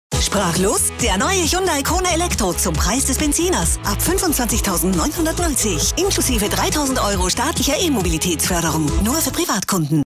Hyundai "Sprachlos", Radio-Spot